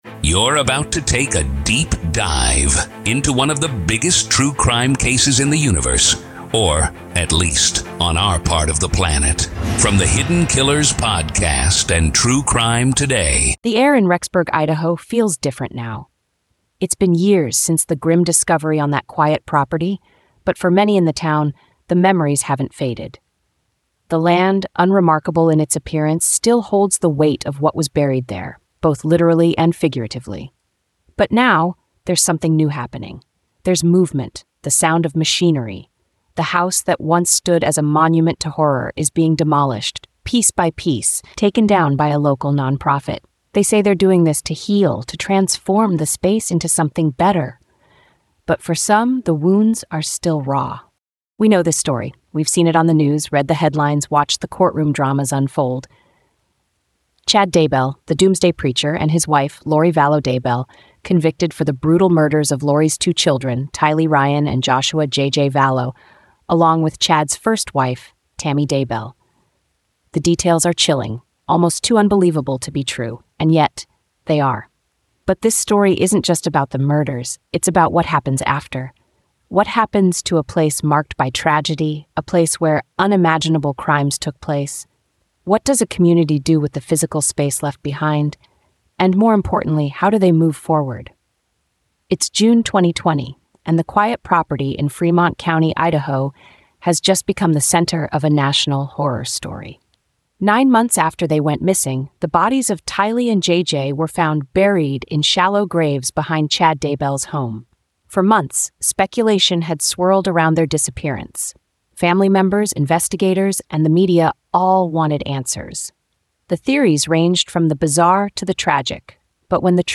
Through interviews, insights, and gripping narrative, we explore the aftermath of one of Idaho’s most notorious crimes and the hope for a brighter future.